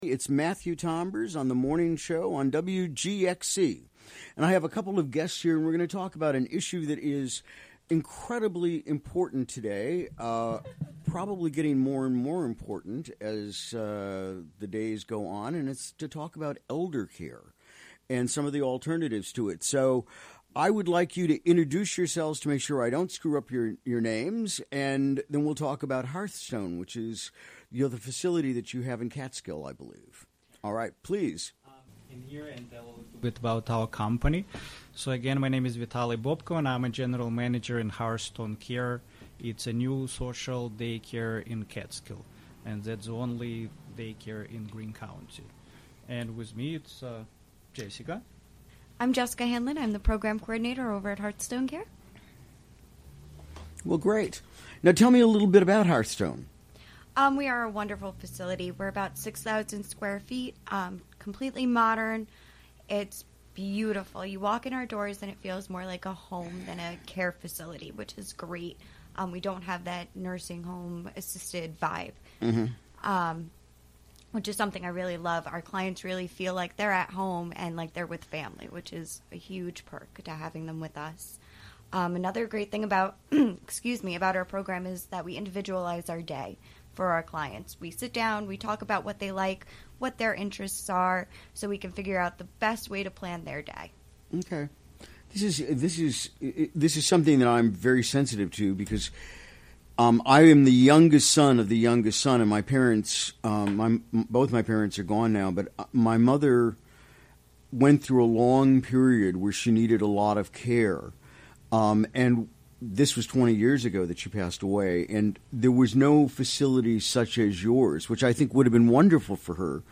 Recorded during the WGXC Morning Show on Wednesday, May 31.